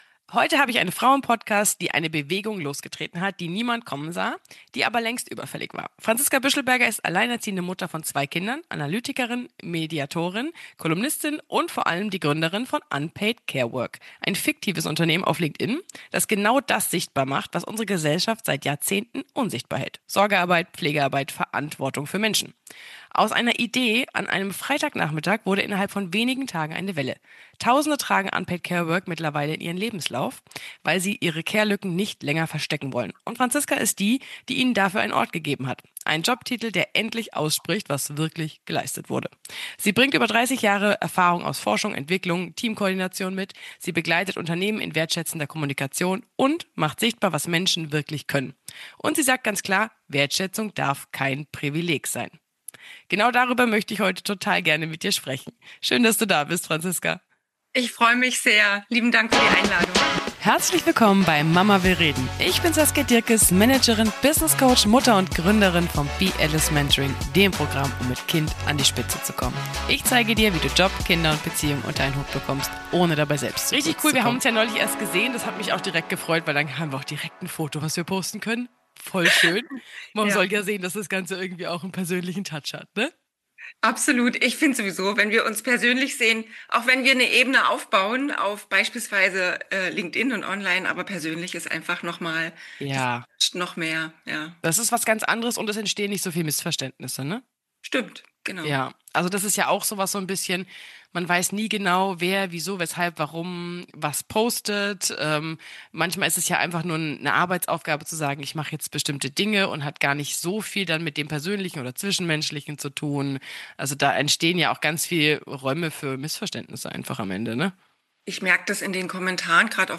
Sonderfolge